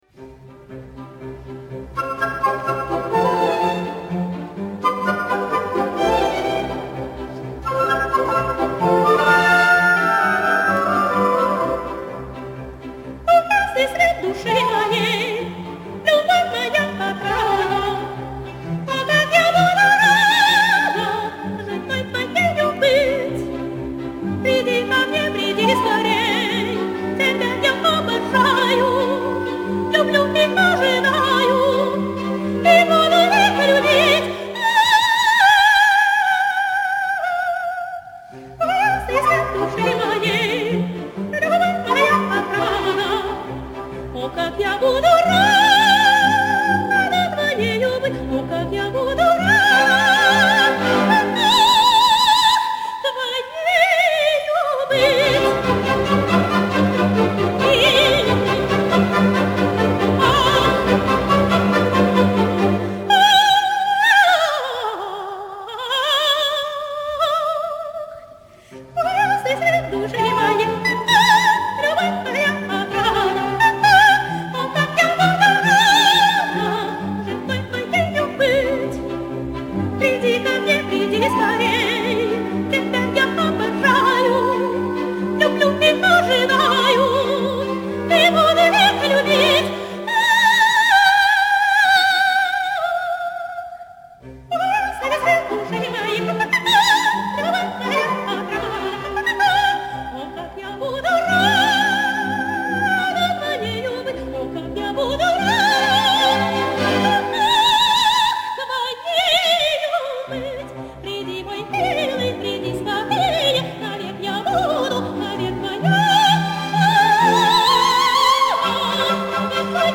Белорусская советская оперная певица (колоратурное сопрано), педагог.